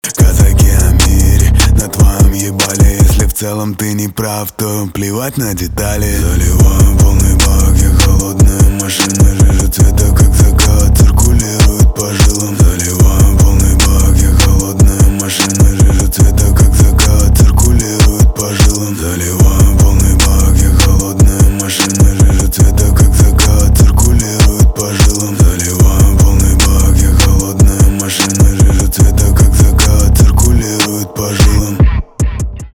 русский рэп
басы
пианино